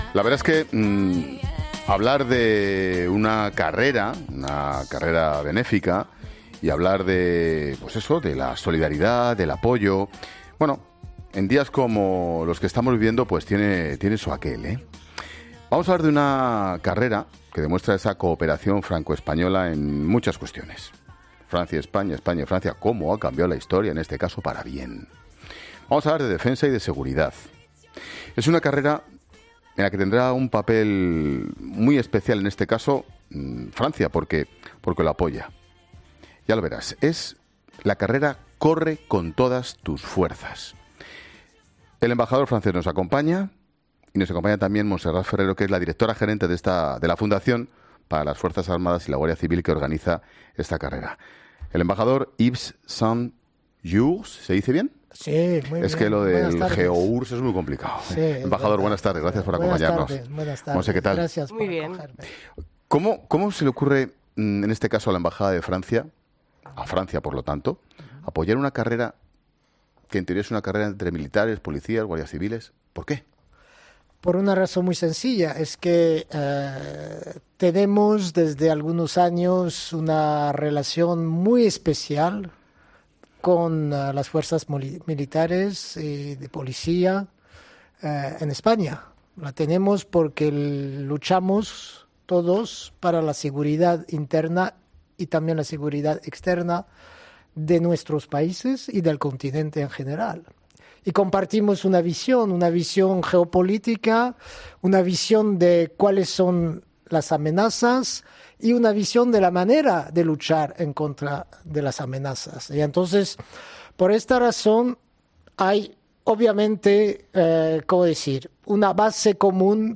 El embajador de Francia en España, Yves Saint-Geours, ha estado en 'La Tarde' con Ángel Expósito para hablar sobre el atentado ocurrido este lunes...